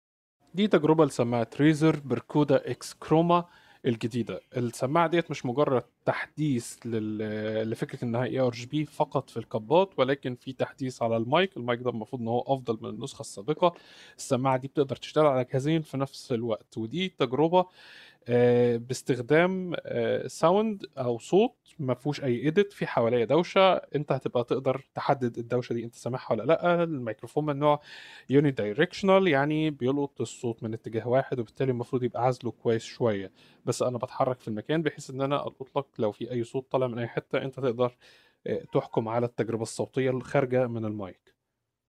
التجربة العامة للسماعه و تجربة الميكروفون :-
الميكروفون الخاص بالسماعة جيد جداً فالصوت الصادر نقى وواضح للغاية اما العزل الخاص بالسماعة بين جيد و جيد جداً ولكن بالتأكيد يوجد أفضل من هذا مع بعض السماعات المنافسة و لكن مع سعر أعلى أيضاً  .
صوت الميكروفون : صوت جيد جدا مع عزل الضوضاء المحيطة بصورة جيدة  أيضاً ويمكن تقييمة بـ 8.2/10
Razer-Barcuda-X-Chroma-Mic-Sample.mp3